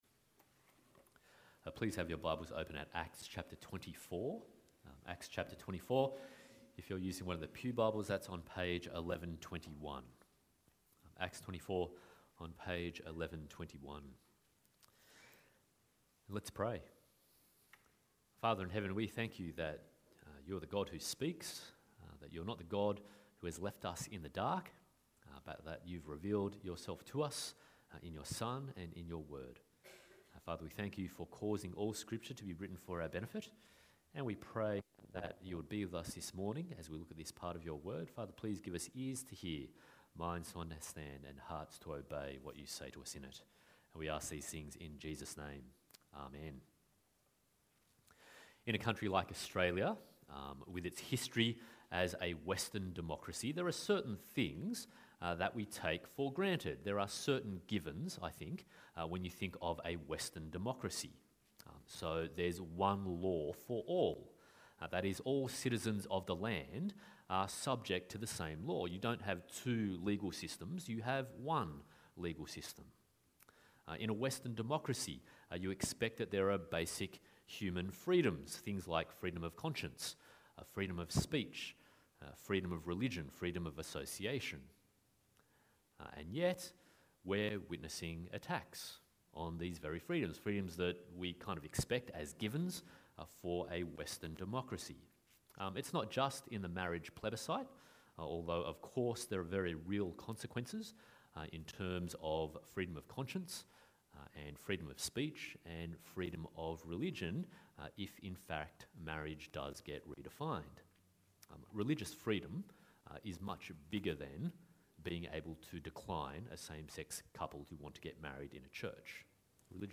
Acts 21-28 Passage: Acts 24:1-27, Ezekiel 37:1-14, Matthew 10:17-20 Service Type: Sunday Morning « What is your greatest need?